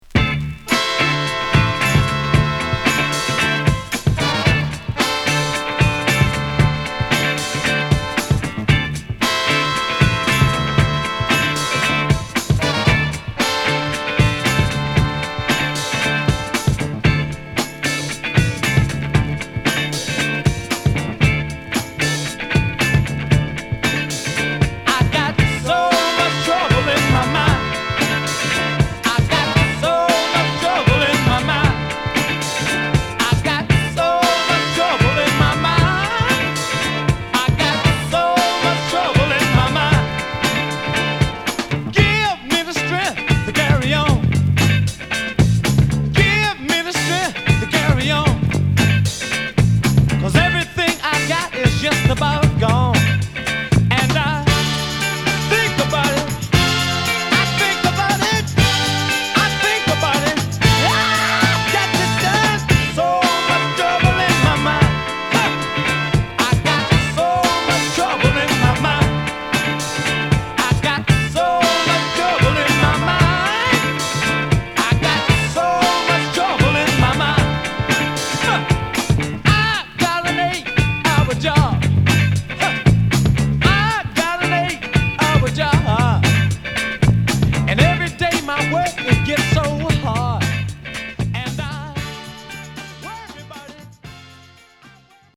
レアグルーヴクラシック！